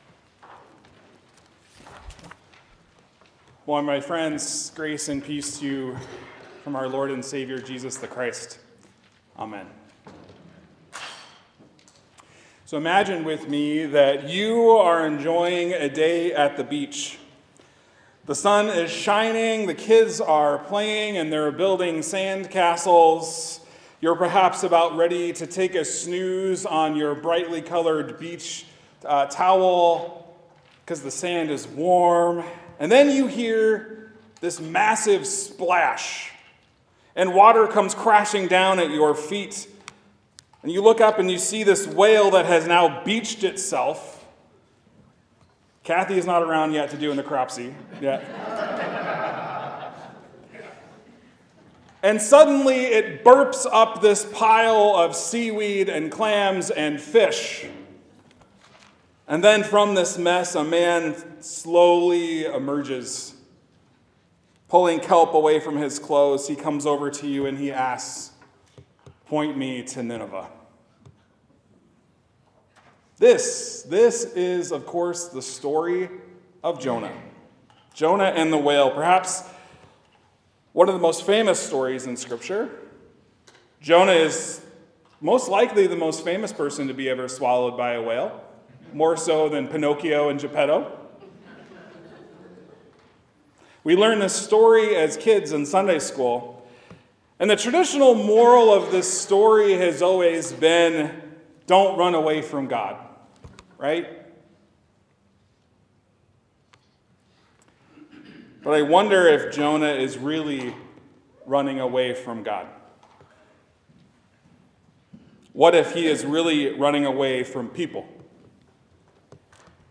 Sermons | Joy Lutheran Church